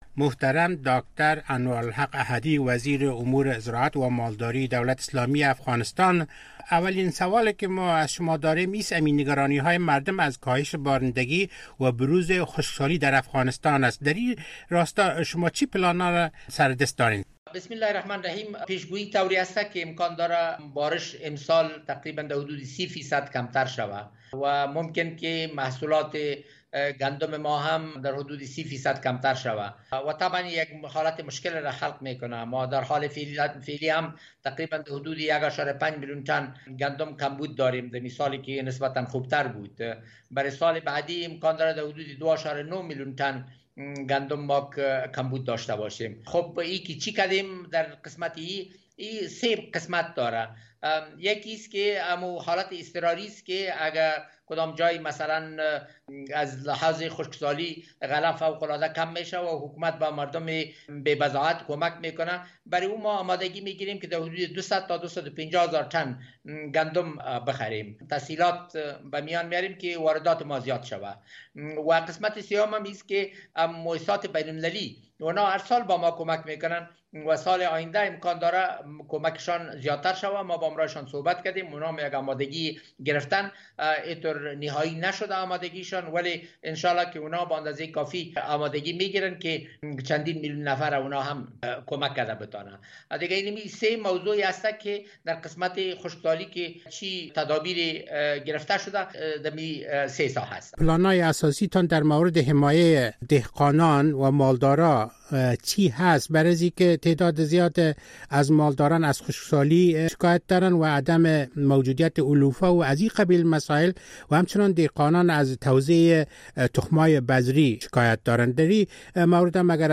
گفتگو با انوارالحق احدی